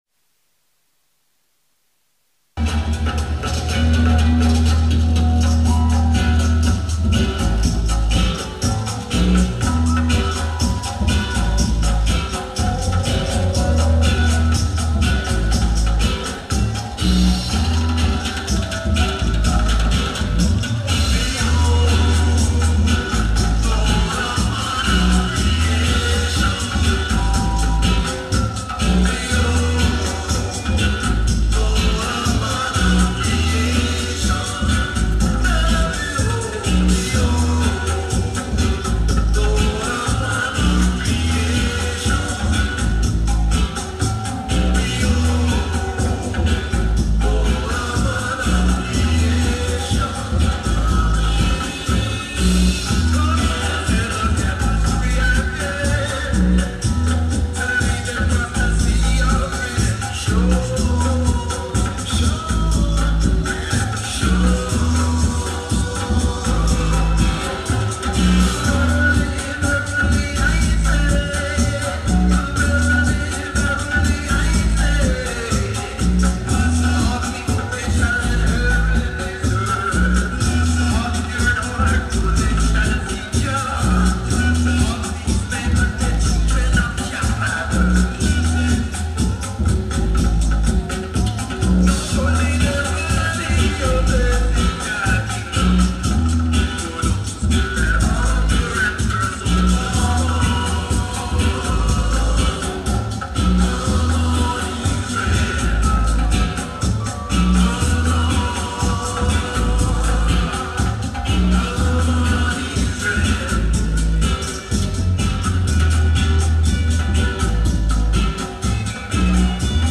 powerful session
King David style